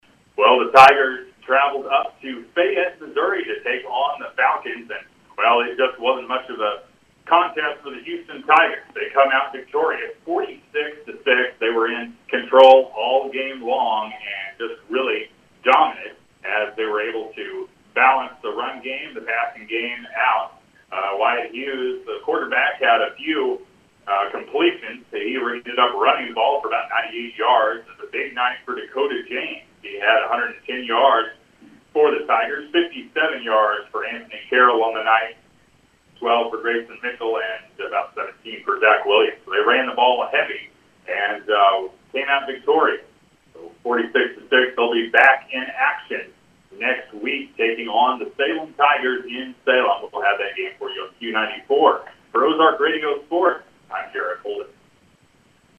Game-2-recap.mp3